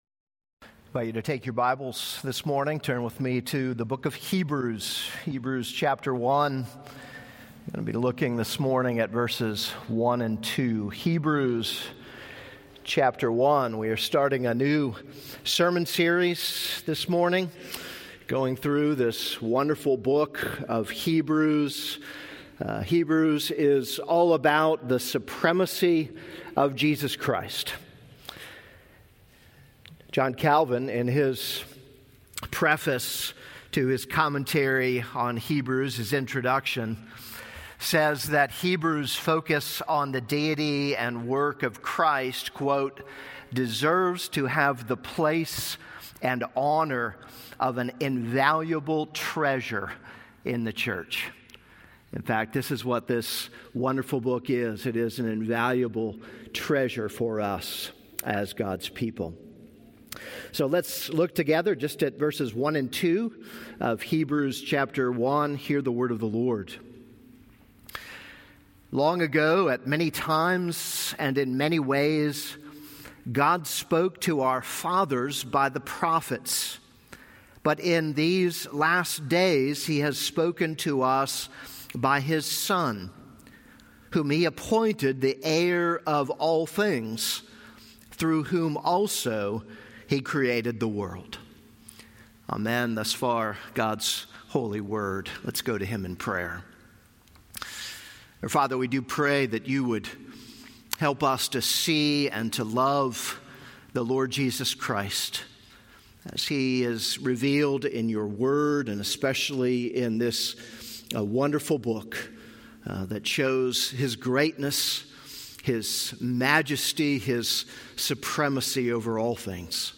This is a sermon on Hebrews 1:1-2.